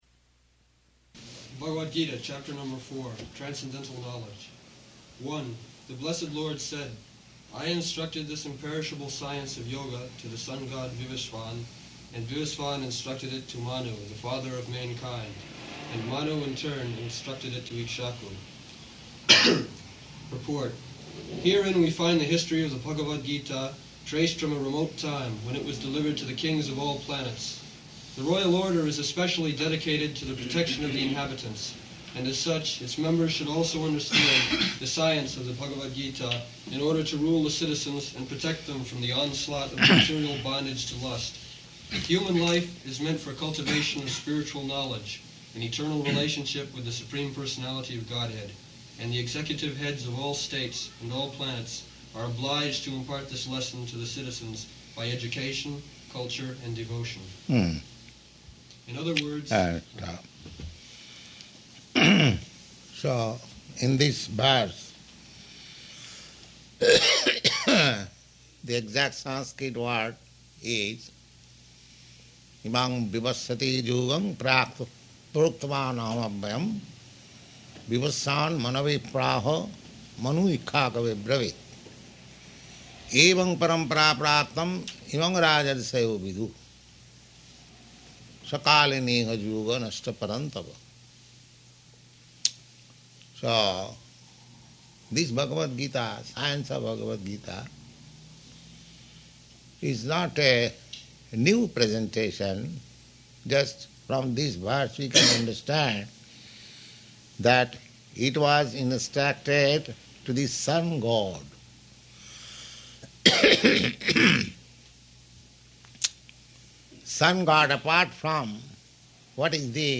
-- Type: Bhagavad-gita Dated: May 9th 1969 Location: Colombus Audio file